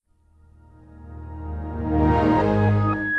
Edited the XP startup/shutdown sounds in Audacity and reversed them.
Windows XP Shutdown.wav